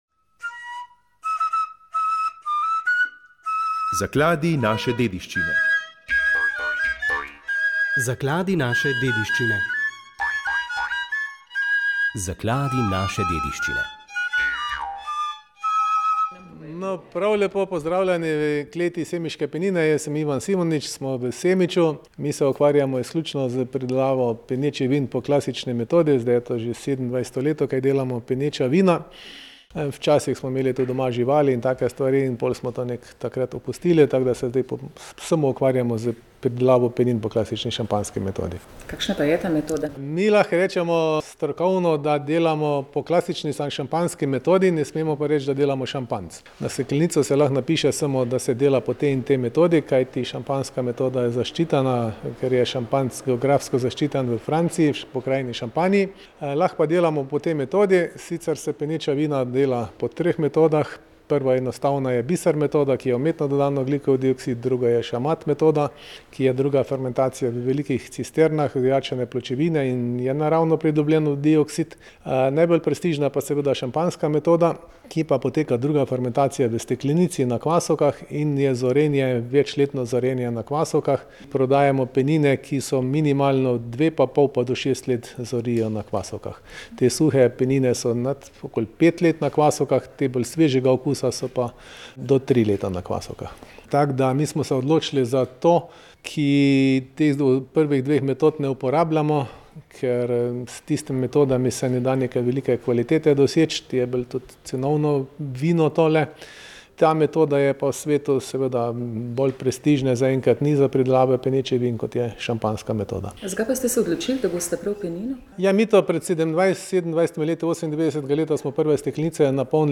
V oddaji iz življenja vesoljne Cerkve ste lahko slišali bistvene poudarke papeževega obiska Indonezije. V studio pa smo povabili štiri Marijine sestre iz Ukrajine, ki so nam povedale, kakšno je njihovo poslanstvo v tej deželi, ki izgublja upanje, da bo nekoč zavladal mir.